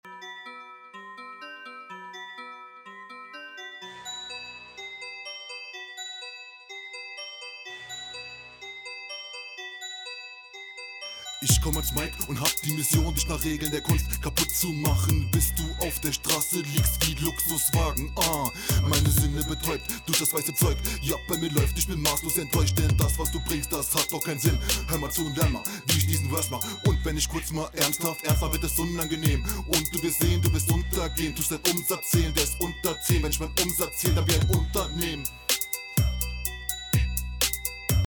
Stimme ist etwas zu leise abgemischt.
Oh Junge Glockenbeat, Mische ist okay, aber die Hihats tun n bissl weh, allg n …